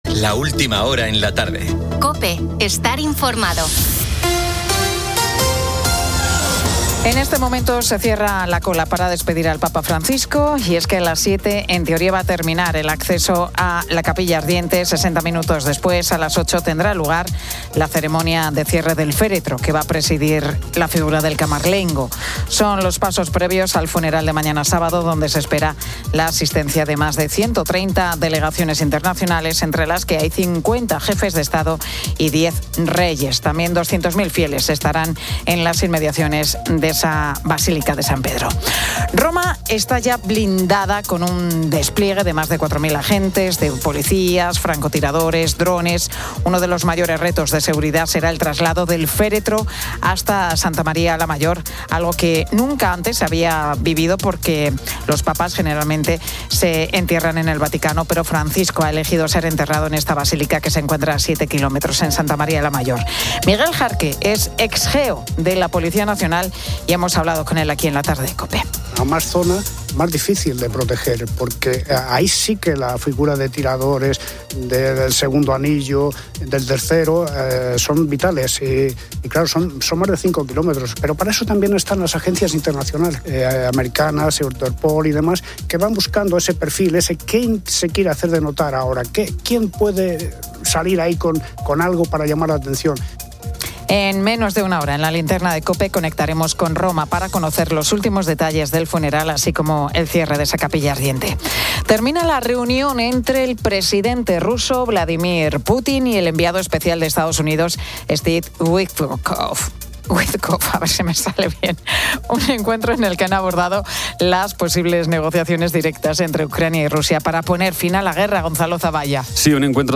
Entrevista a Beret antes de que actúe en la Fiesta de la Resurrección y conecta con Roma en los últimos minutos de la capilla ardiente del Papa Francisco.